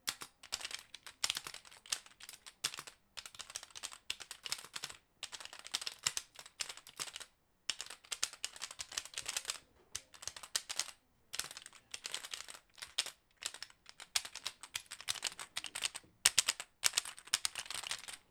mechanical keyboard tapping.wav
Typing very fast on a plastic mechanical keyboard in the living room, recorded with a Tascam DR 40.
mechanical_keyboard_tapping_4QG.wav